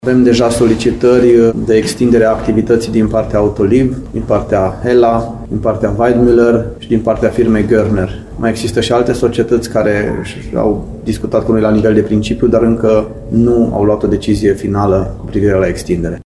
Primarul Lugojului spune că mai sunt și alte solicitări, dar care momentan sunt doar la nivel de discuții.